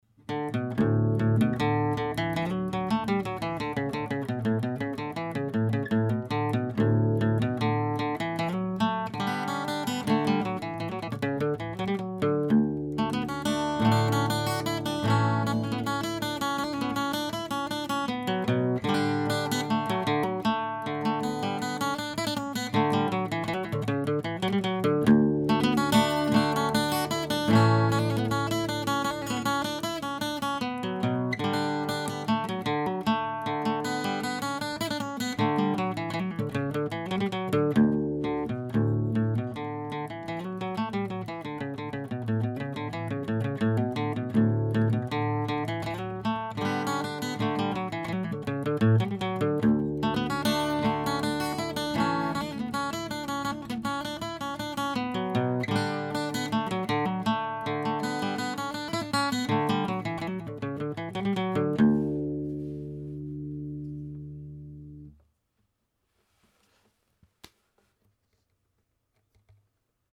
His original .wav recording does not crack, quite! But hits about neg 2 a couple of times!